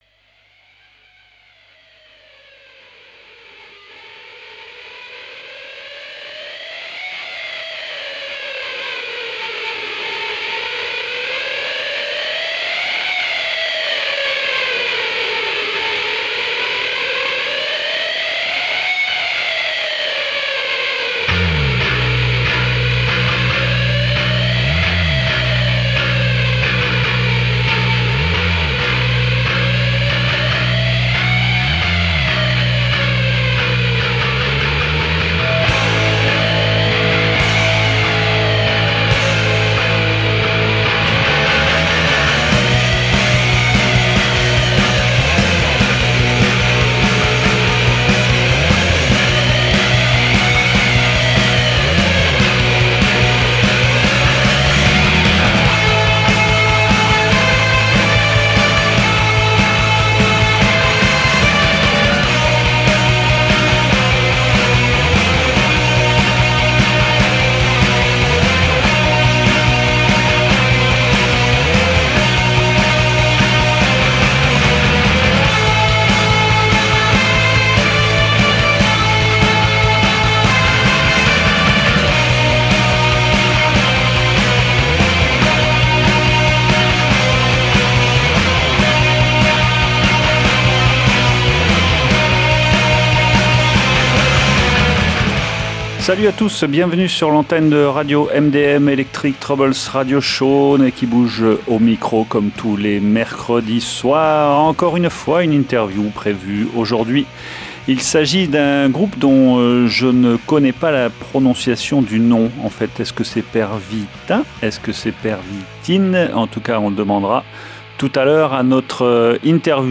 Programmation musicale éclectique, multi-générationnelle, originale, parfois curieuse, alternative et/ou consensuelle, en tous les cas résolument à l’écart des grands réseaux commerciaux.